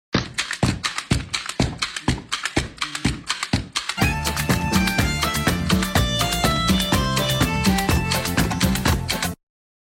hahaha sound effects free download